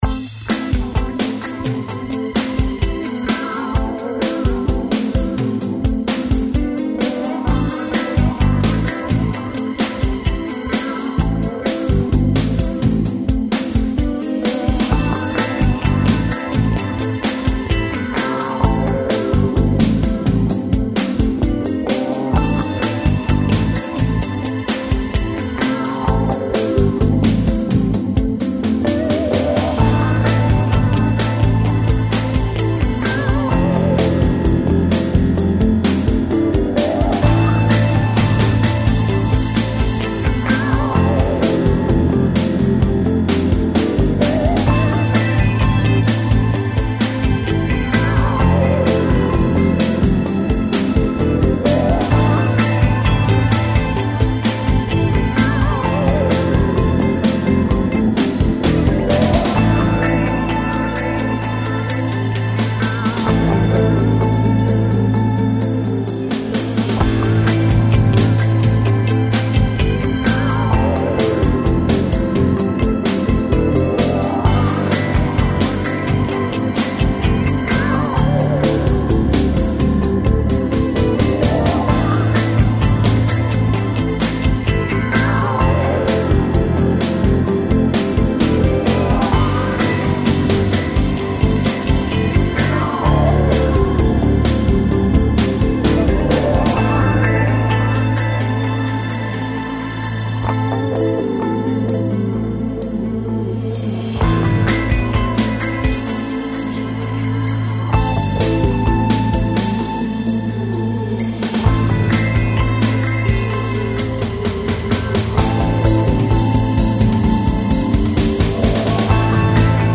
go pro - sport - rock - adrenaline - epique